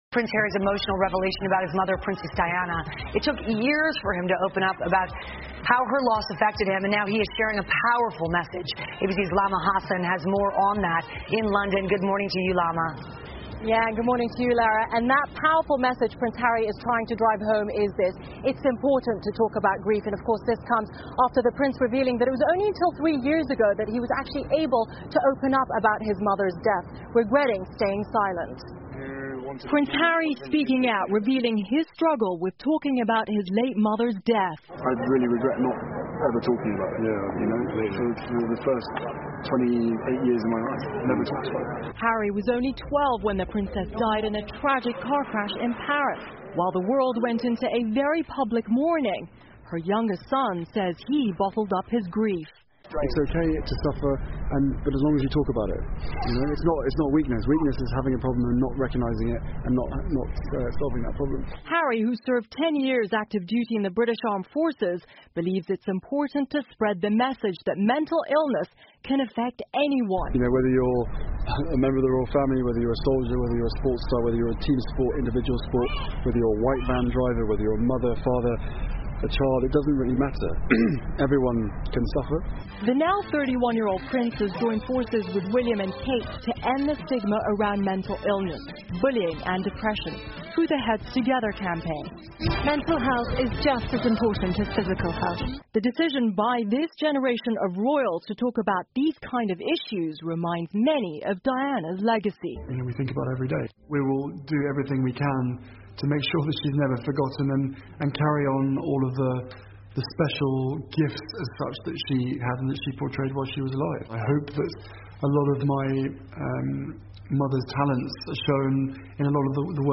访谈录 哈里王子谈论母亲戴安娜王妃之死 听力文件下载—在线英语听力室